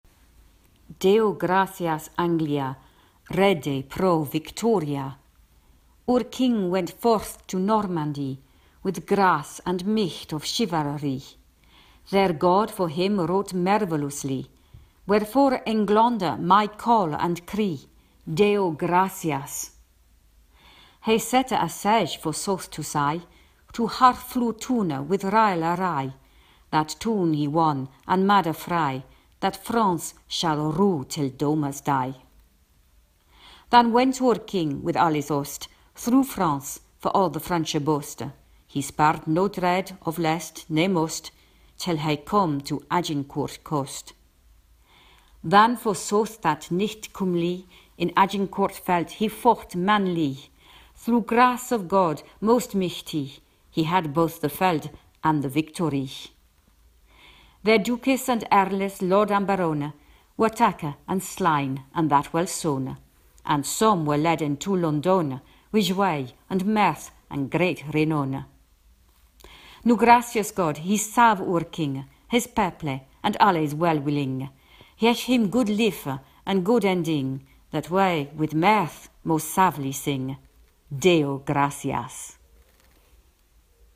Prononciation Deo Gracias
Je vous envoie par le présent l’enregistrement que j’ai fait il y a quelques années pour la prononciation de Deo Gracias Anglia, The Agincourt Carol. La qualité son n’est pas extraordinaire mais je pense que c’est encore comprehensible.
Agincourt-pronunciation.mp3